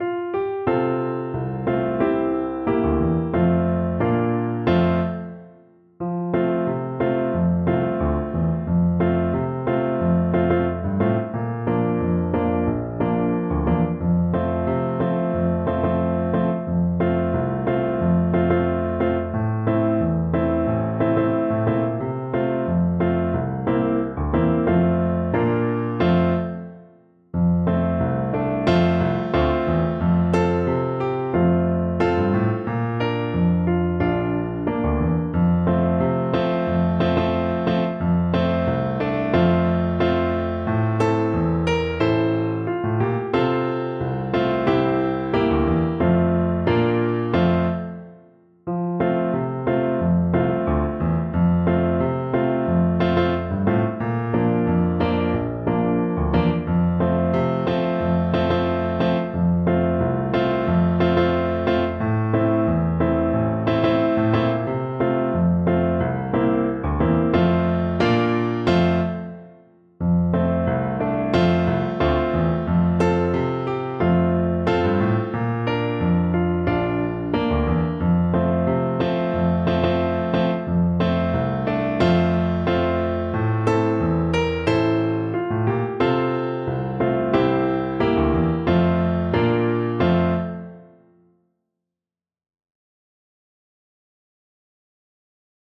Flute version
4/4 (View more 4/4 Music)
Moderato =c.90